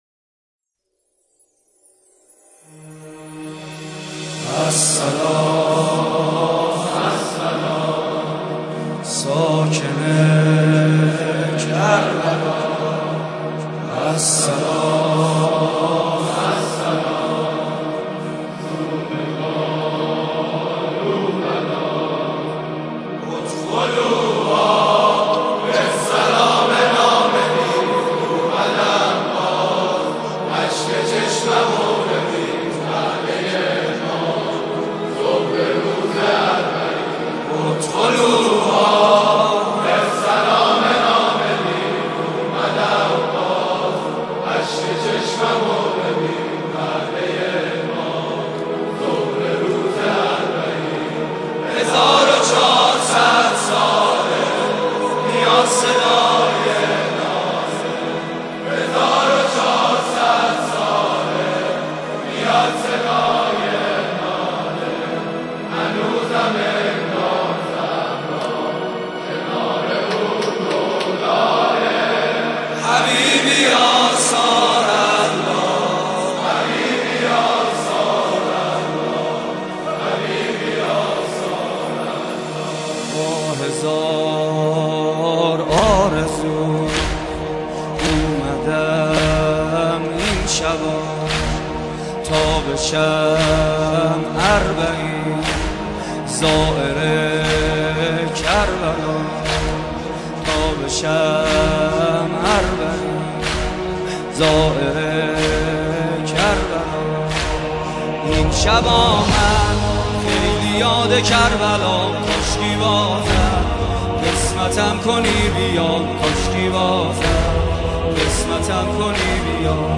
استدیویی اربعین